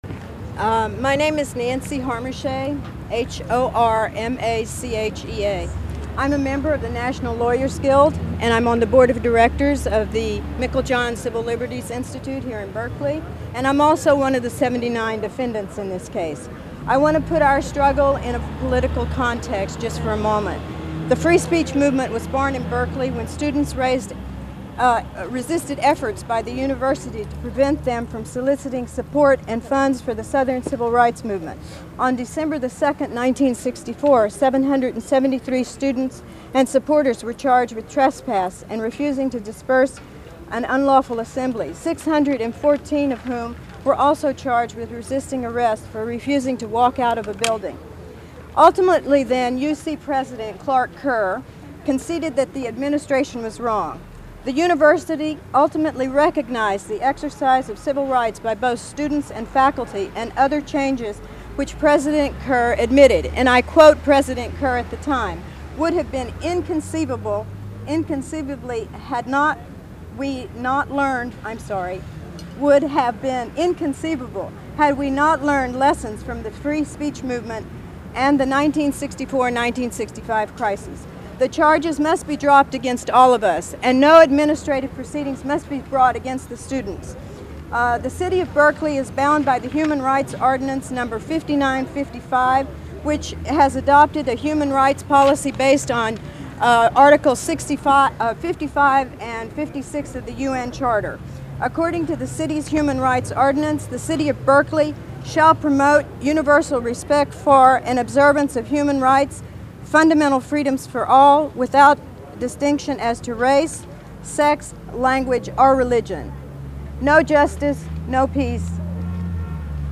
Statements from NLG and SJP from a 4/30 press conference held in front of the Berkeley courthouse